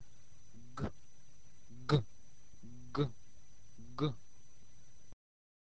¥_´ - letter like English g. This is a ringing g. Generally, this letter very seldom appeared in Ukrainian words.